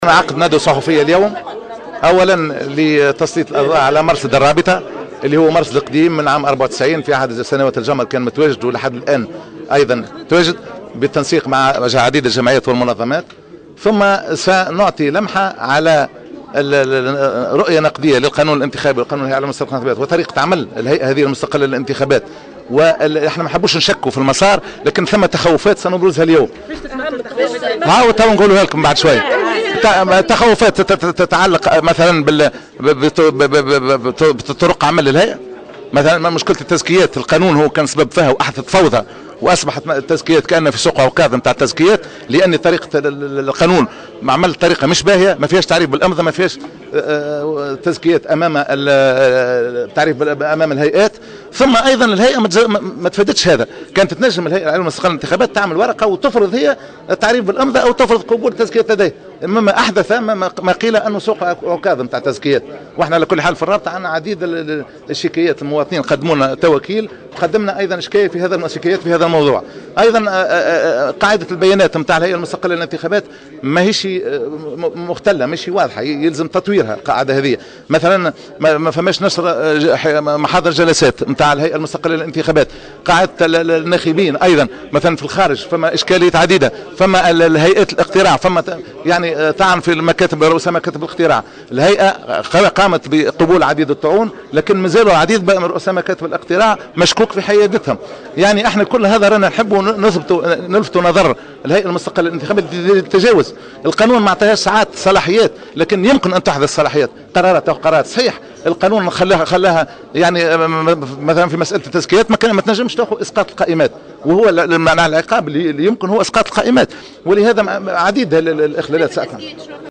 وقال على هامش ندوة عقدتها الرابطة لا نريد التشكيك في المسار الانتخابي لكن لدينا تخوفات تتعلق بطرق عمل الهيئة العليا المستقلة للانتخابات من ذلك تعاملها مع مشكلة التزكيات التي أحدثت فوضى والتي لم تقم الهيئة بتفاديها"،وفق تعبيره.